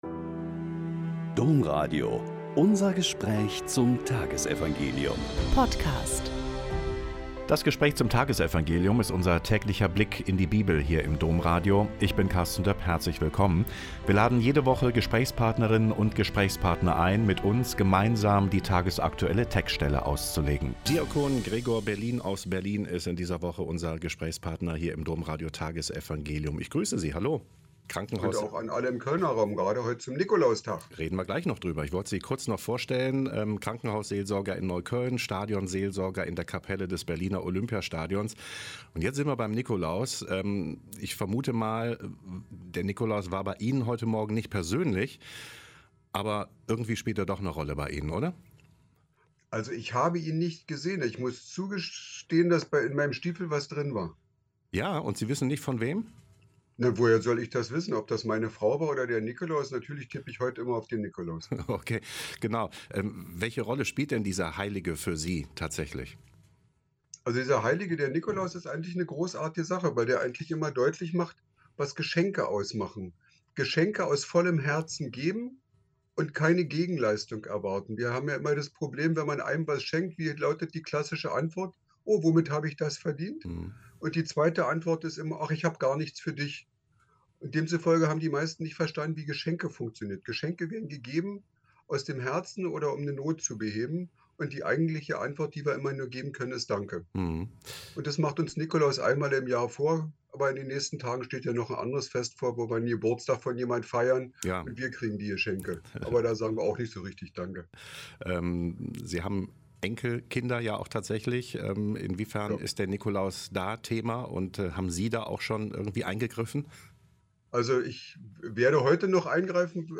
Mt 9,27-31 - Gespräch